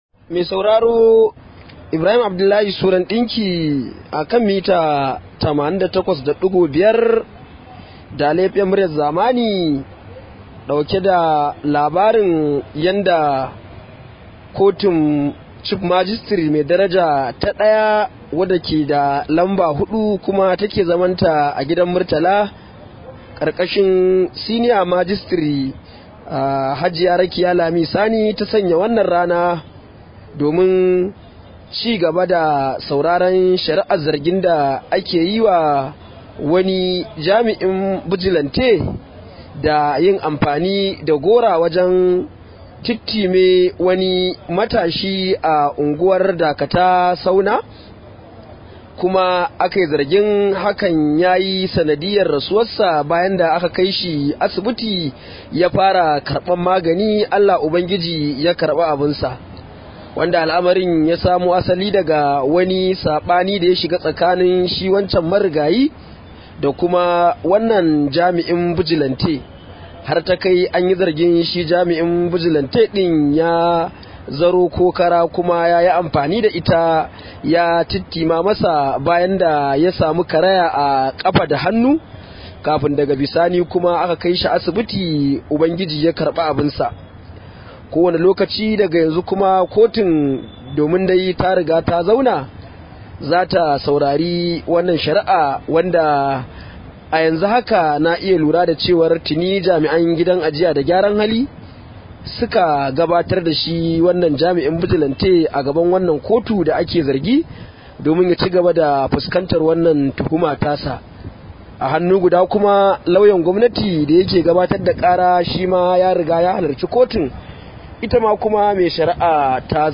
Rahoto: An ci gaba da shari’ar Dan Bijilante da ake zargi da kashe matashi a Kano